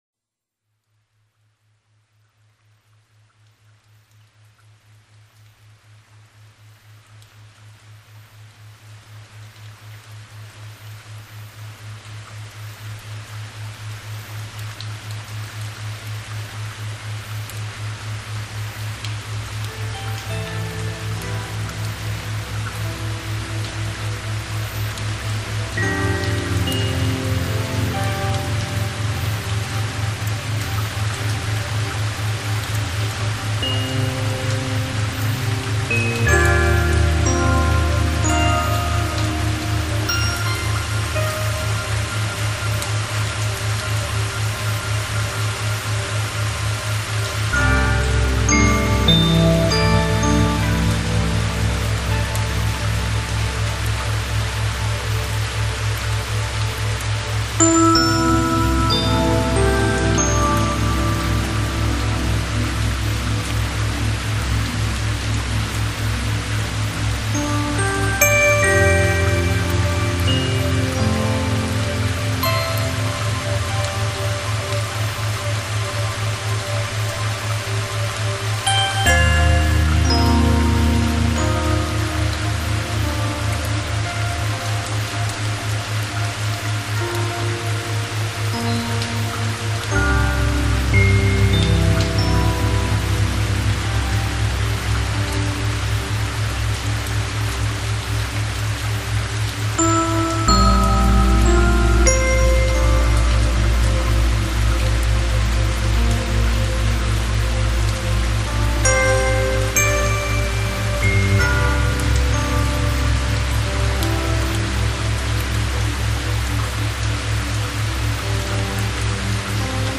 The meditation track below is a bi-naurial beats tones recording.This means that it is designed to be listened to with headphones. Done this way the tones will slow down your brainwaves, as happens with meditation - so aiding you on your path.These recordings must not be listened to whilst driving or operating machinery because they will make you less alert.